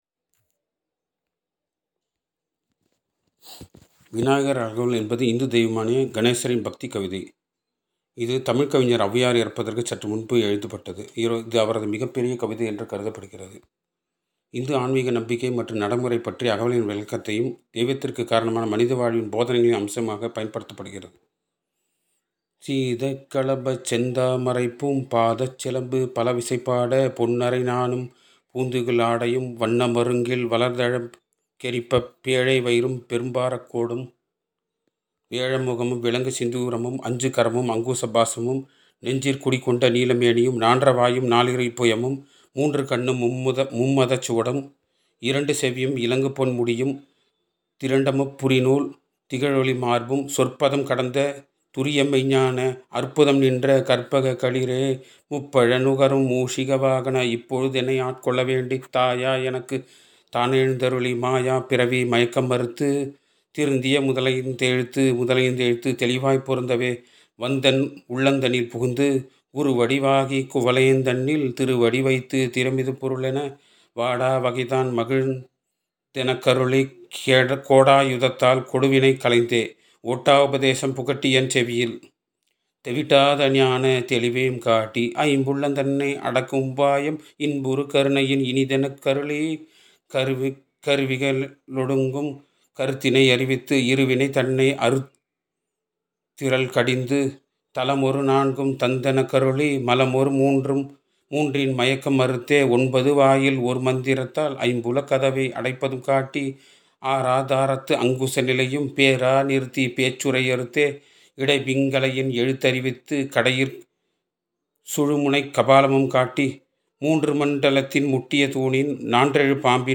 VINAYAGAR-AGAVAL-VINAYAGAR-SONG.mp3